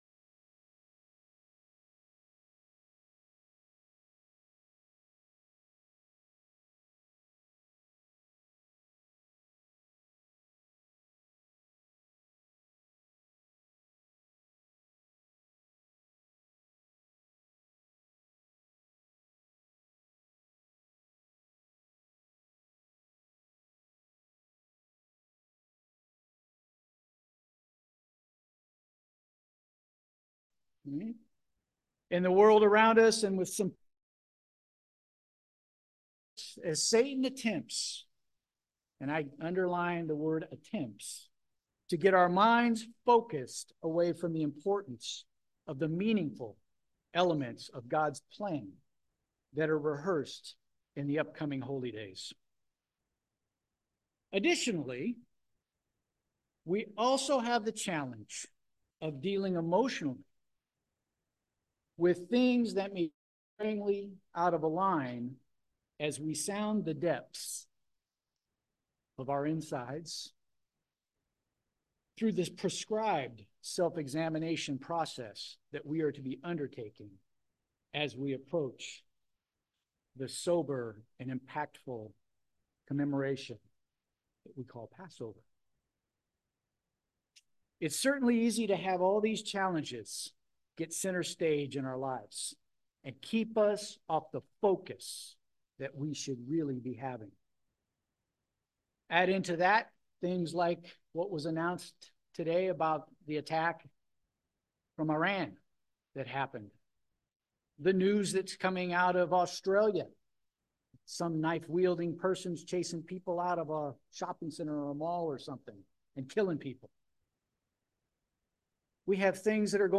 Sermons
Given in Petaluma, CA San Francisco Bay Area, CA